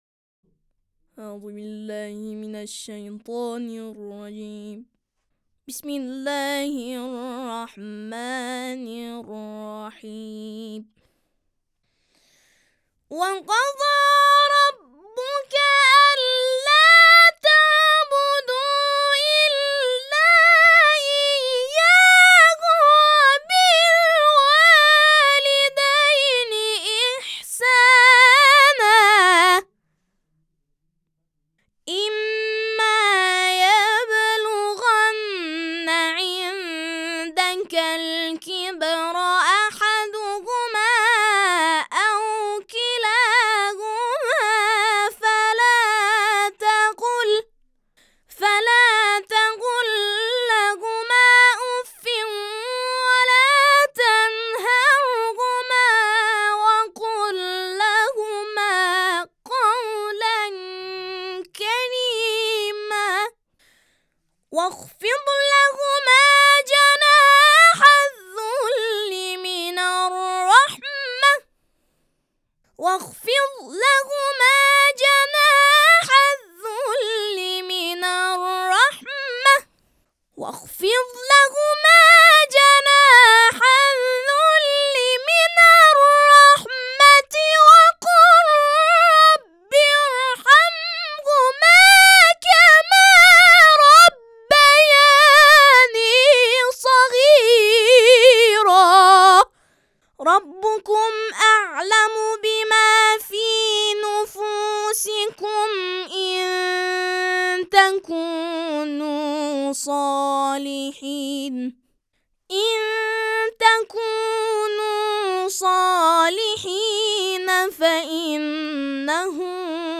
فینال اولین دوره جشنواره ترنم وحی، ۱۸ اسفندماه از سوی مؤسسه اظهرالجمیل در جزیره کیش برگزار شد.
تلاوت تقلیدی